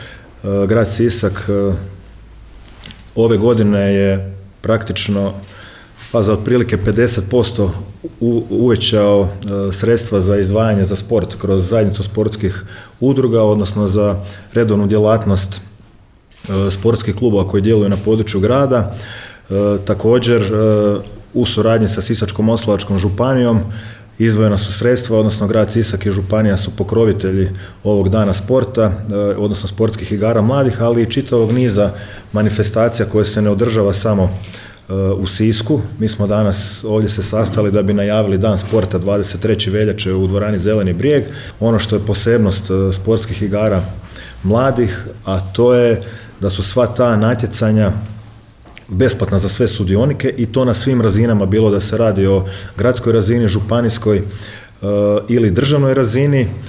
Gradonačelnik Grada Siska Domagoj Orlić podsjetio je da epitet Siska kao grada sporta nije slučajan